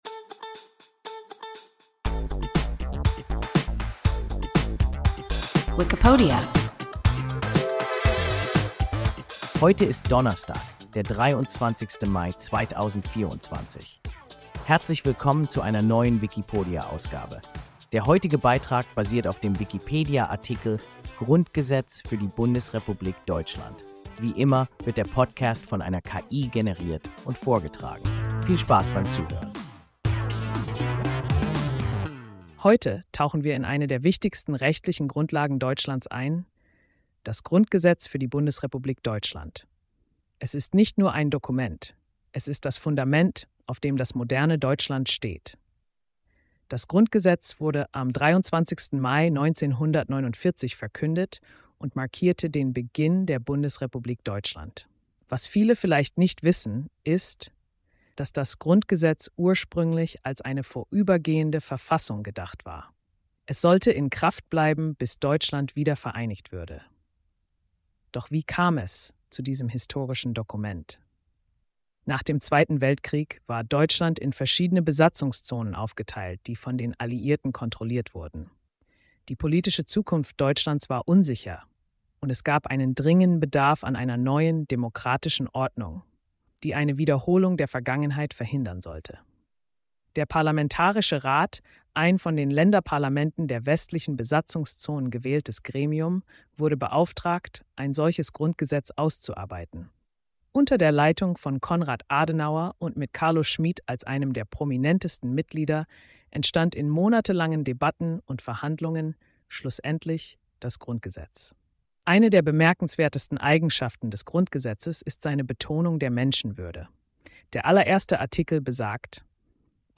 Grundgesetz für die Bundesrepublik Deutschland – WIKIPODIA – ein KI Podcast